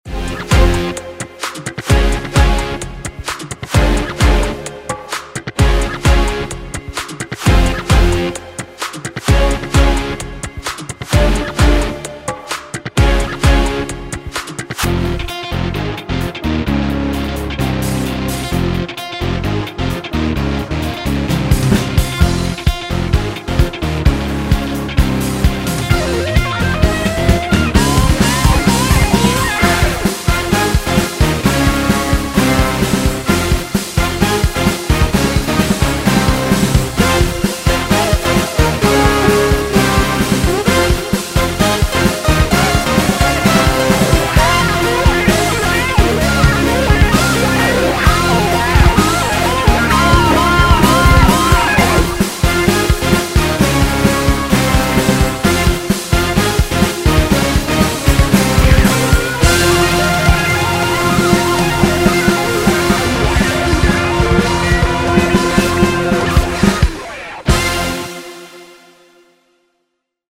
ז'אנרDance
BPM130